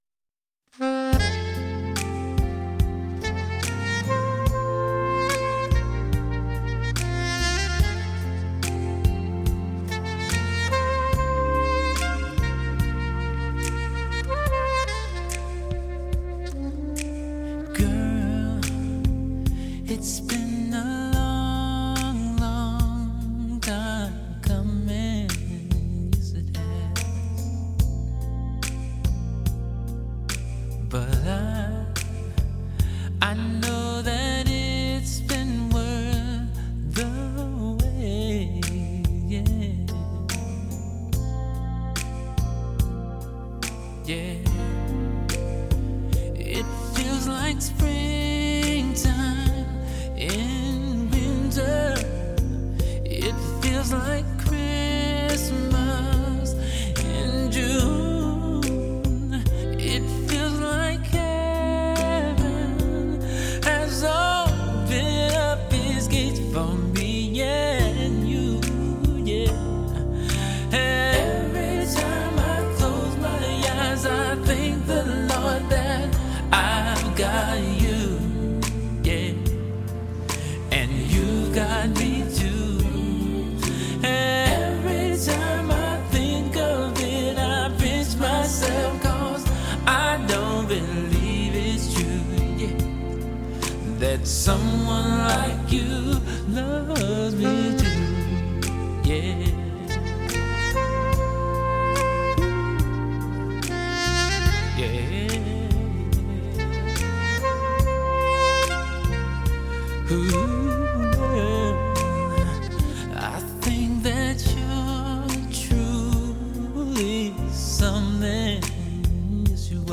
专辑中迷人的旋律和萨克斯飘逸的声音会带给你无限音乐美感，喜欢流行萨克斯音乐的朋友万勿错过。